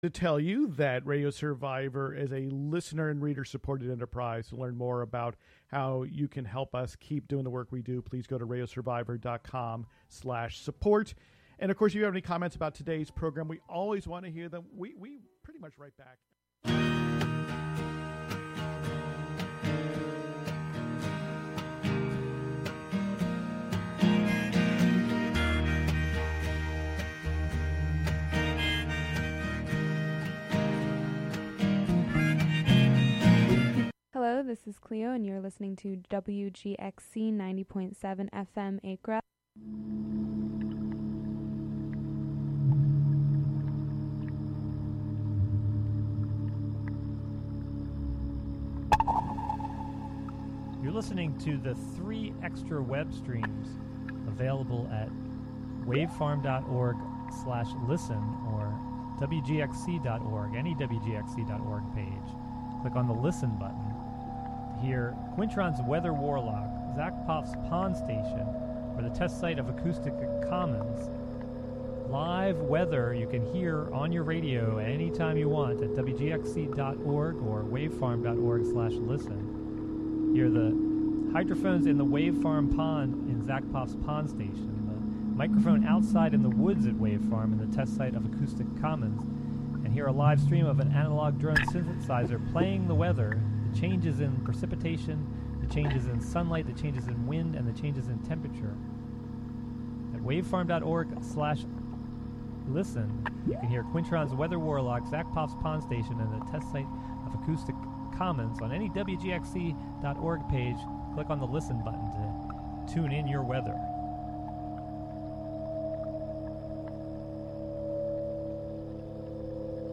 Contributions from many WGXC programmers.
The show is a place for a community conversation about issues, with music, and more. Saturday the emphasis is more on radio art, and art on the radio.